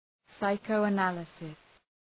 Προφορά
{,saıkəʋə’nælısıs}